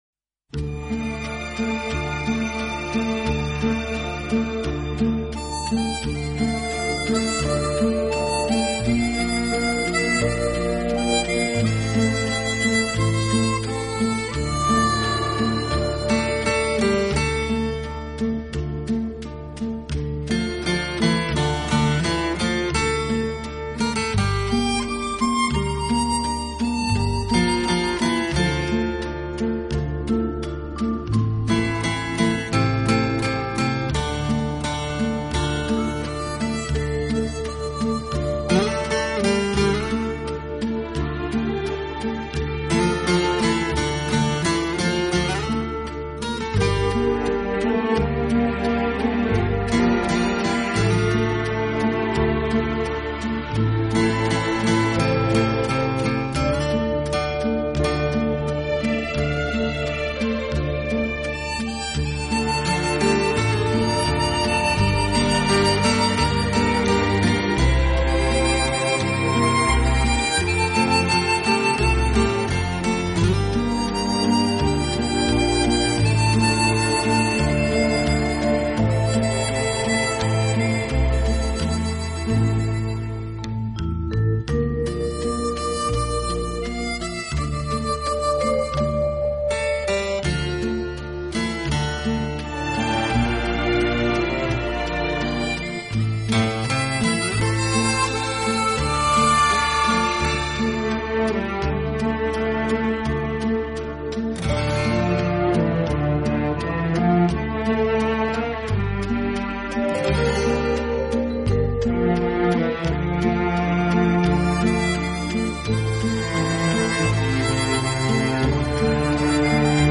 专辑歌手：纯音乐